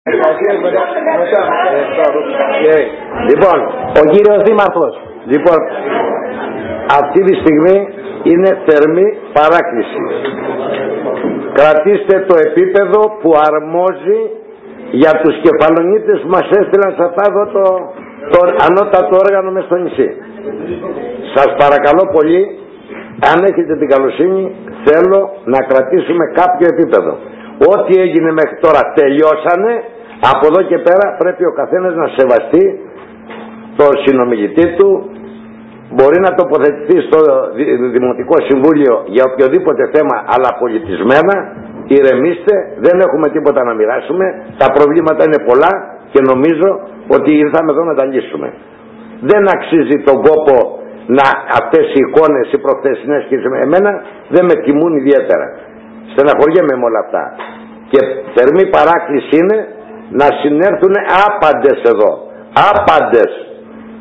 Στο τέλος όταν καταλάγιασαν οι ….εχθροπραξίες, ο Δήμαρχος αρκετά στεναχωρημένος είπε τα εξής:
ΔΗΛΩΣΗ ΔΗΜΑΡΧΟΥ
ΔΗΛΩΣΗ-ΔΗΜΑΡΧΟΥ.mp3